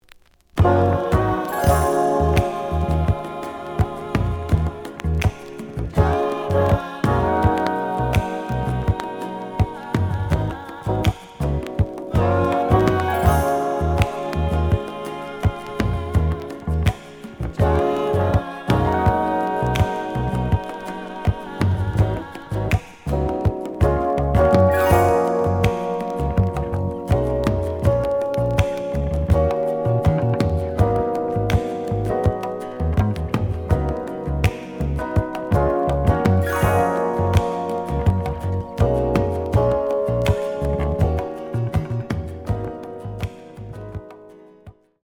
(Instrumental)
The audio sample is recorded from the actual item.
●Format: 7 inch
●Genre: Soul, 80's / 90's Soul